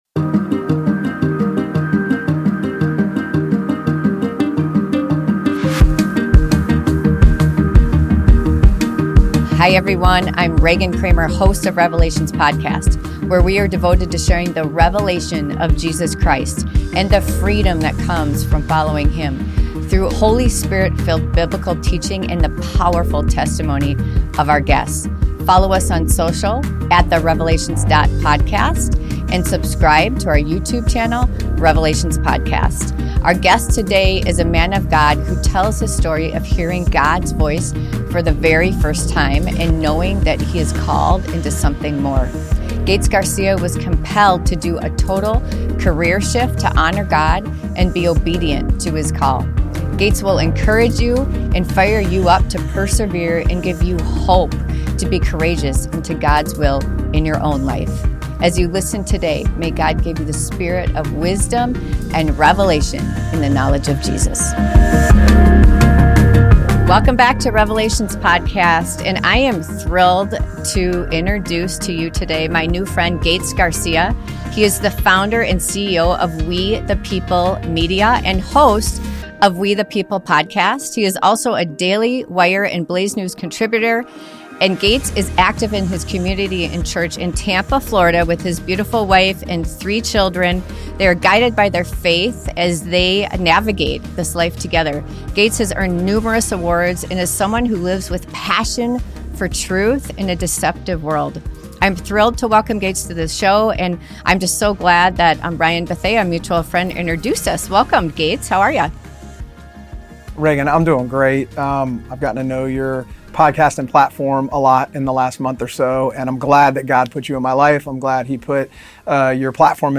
Faith, Family and Freedom: A Conversation (Ft.